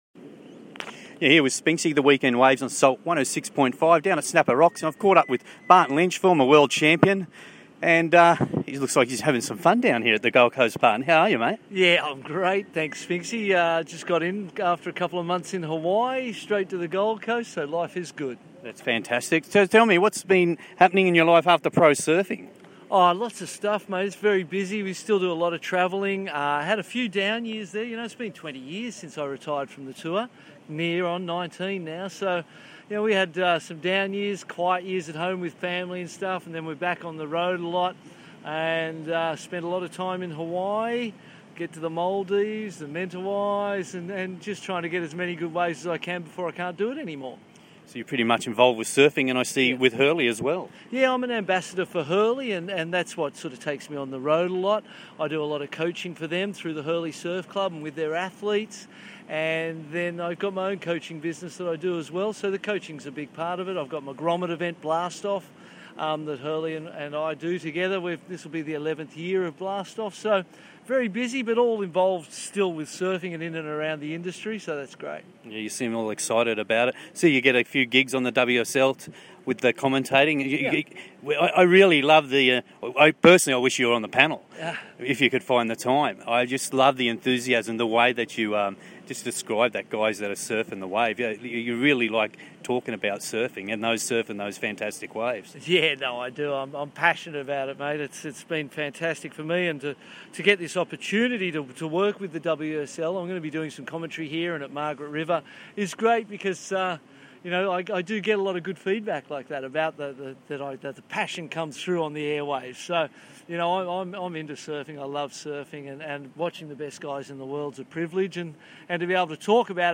The Week In Waves - Interview Barton Lynch.
Last year down at the Quiky Pro I had the pleasure of interviewing some Aussie Surfing Legends.